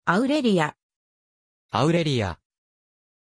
Aussprache von Aurelia
pronunciation-aurelia-ja.mp3